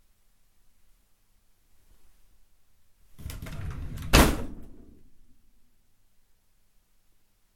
Fast/ Close Top Drawer
Duration - 7s Environment - Bedroom, absorption of curtains, carpet and bed. Description - Close, pulled fast, grabs, slams, wooden drawer, bangs close